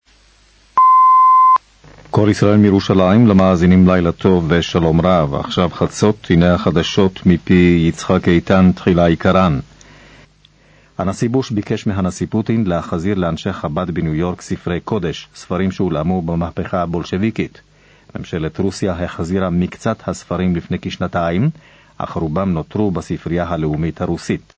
קטע מחדשות קול ישראל